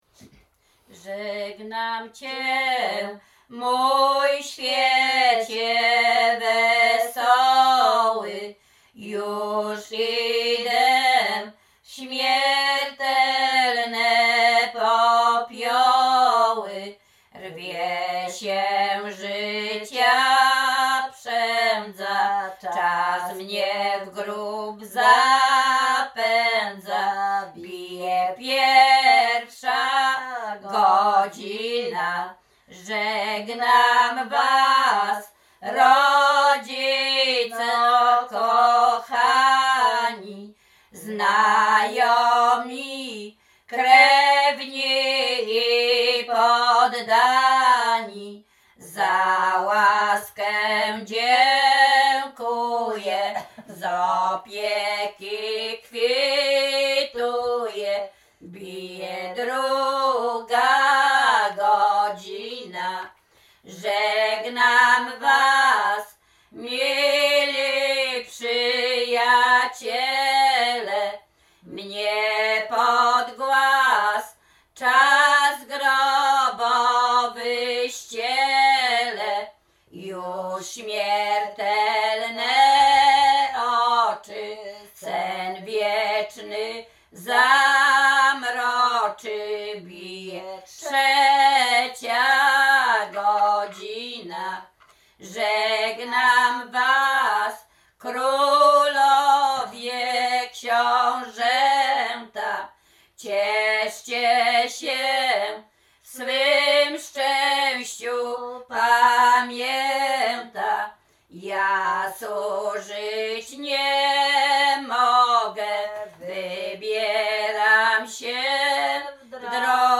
Śpiewaczki z Czerchowa
województwo łódzkie, powiat zgierski, gmina Ozorków, wieś Czerchów
Pogrzebowa
Array nabożne katolickie pogrzebowe